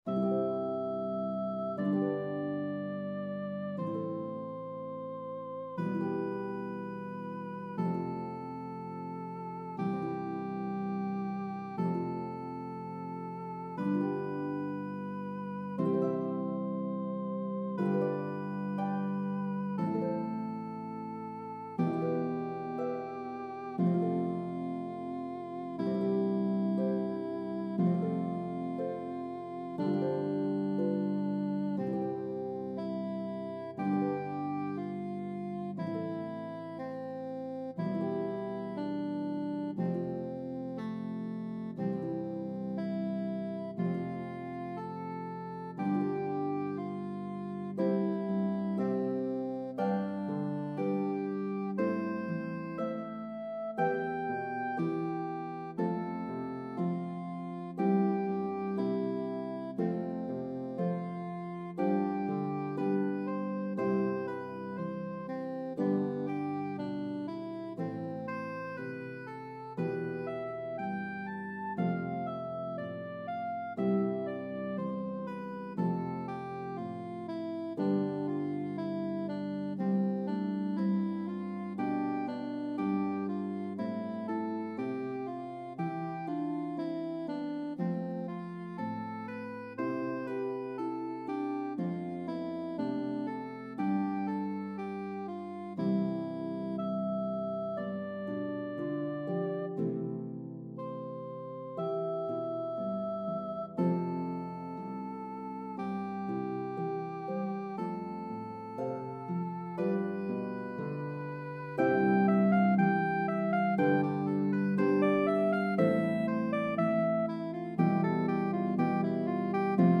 Harp and Soprano Saxophone version